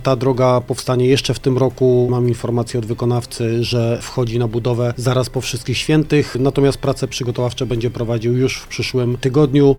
Rozstrzygnięty został przetarg na remont ulicy Długiej w Brzózie. To inwestycja długo oczekiwana przez mieszkańców, mówi Hubert Czubaj, wójt gminy Głowaczów: